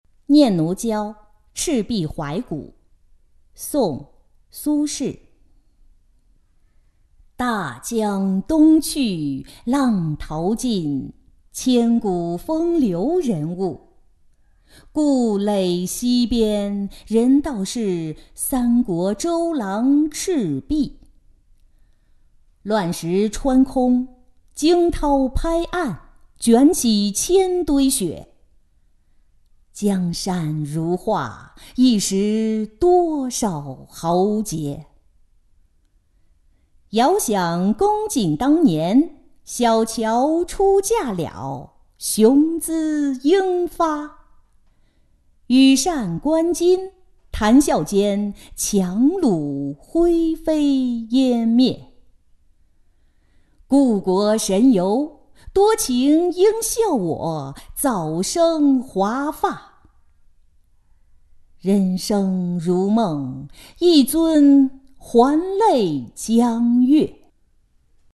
念奴娇·赤壁怀古-音频朗读